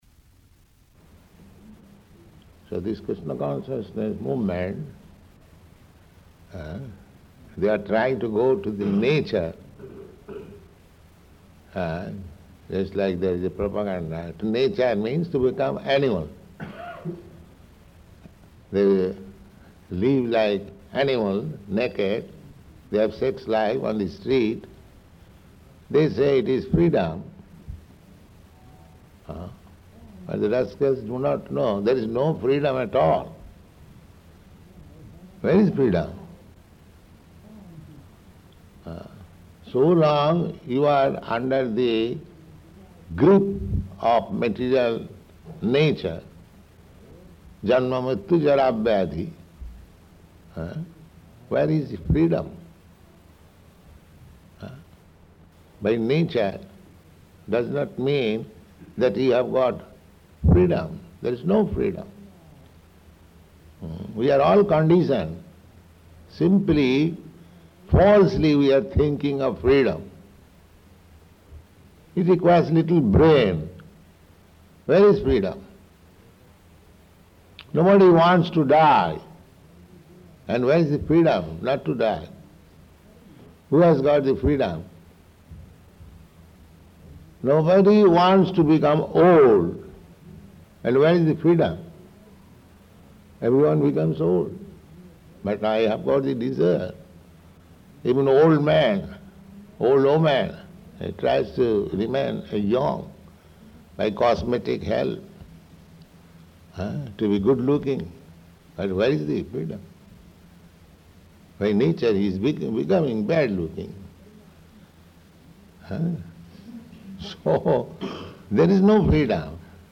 Initiation Lecture
Initiation Lecture --:-- --:-- Type: Initiation Dated: September 7th 1971 Location: London Audio file: 710907IN-LONDON.mp3 Prabhupāda: So this Kṛṣṇa consciousness movement...